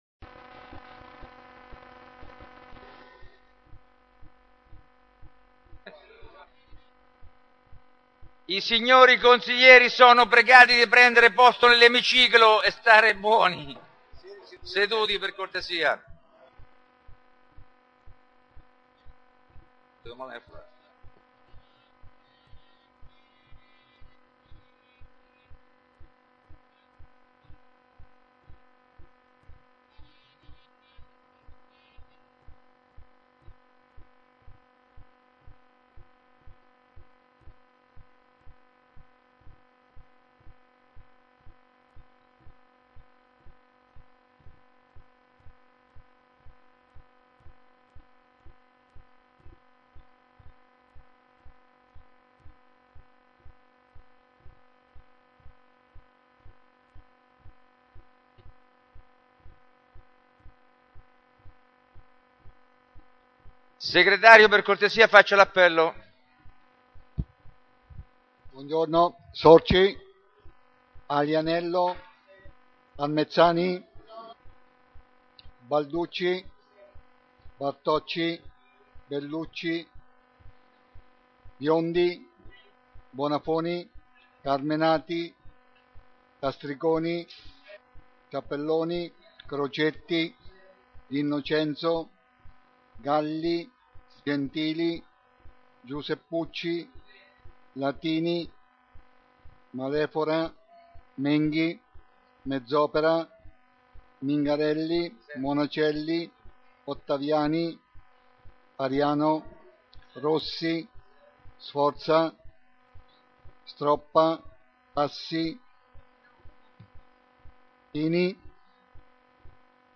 Ai sensi dell'art. 20, comma 7, dello Statuto Comunale e dell'articolo 14 del regolamento consiliare, il Consiglio Comunale è convocato presso Palazzo Chiavelli - sala consiliare (Piazzale 26 settembre 1997) giovedì 29 aprile alle ore 9.00